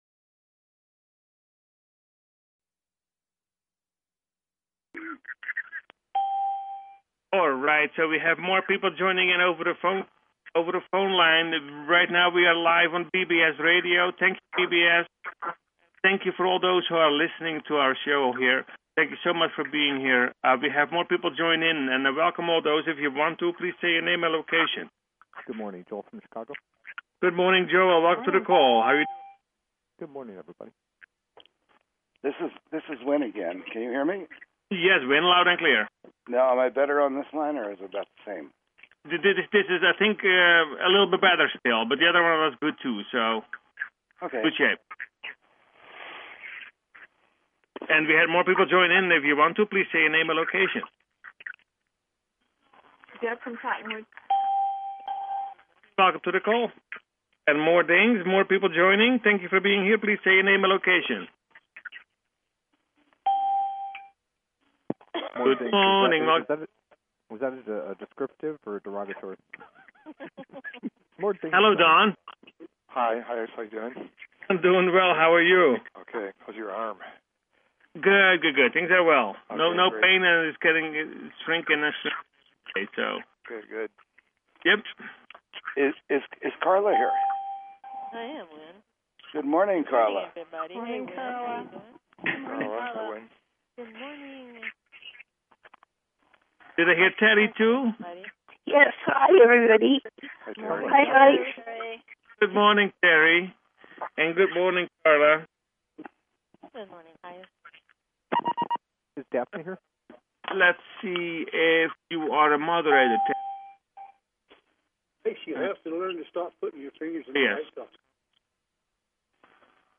Talk Show Episode, Audio Podcast, Personal_Planetary_Healing_Meditation and Courtesy of BBS Radio on , show guests , about , categorized as
Meditation